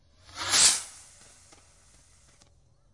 烟花 " 瓶装火箭01
描述：使用Tascam DR05板载麦克风和Tascam DR60的组合使用立体声领夹式麦克风和Sennheiser MD421录制烟花。我用Izotope RX 5删除了一些声音，然后用EQ添加了一些低音和高清晰度。
Tag: 高手 焰火 裂纹